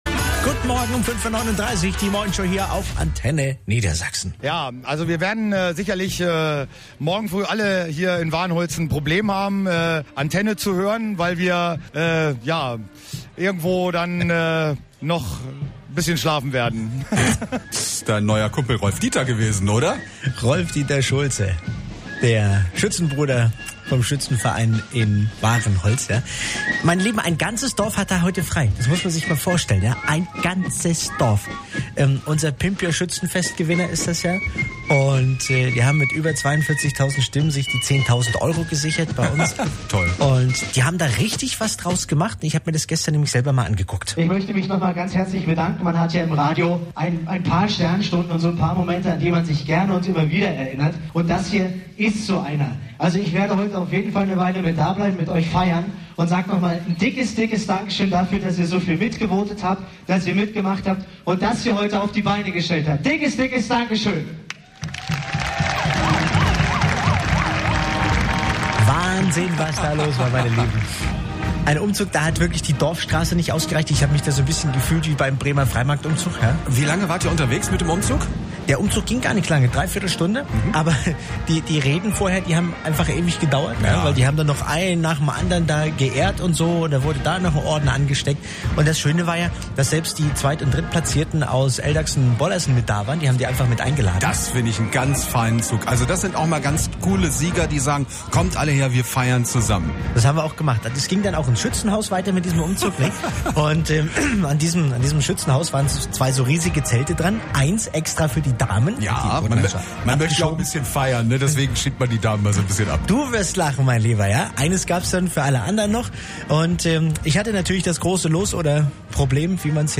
Mitschnitt Antenne Niedersachsen Montag, 01.06. 5:39 Uhr